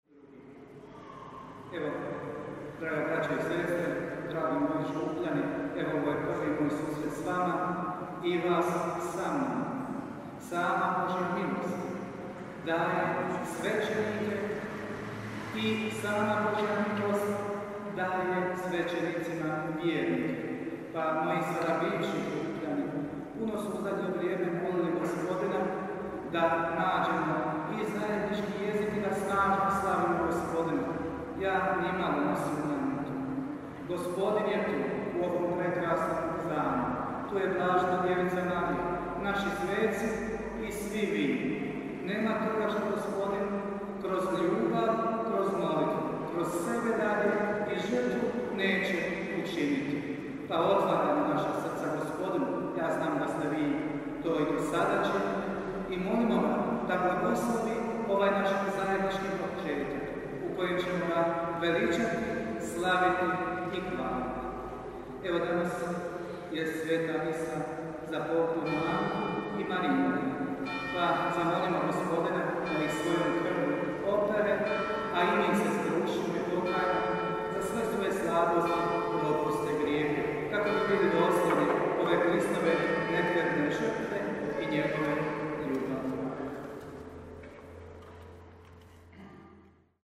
UVOD u  sv. MISU: